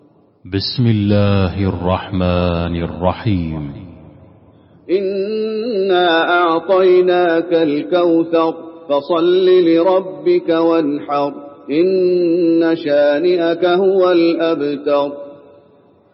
المكان: المسجد النبوي الكوثر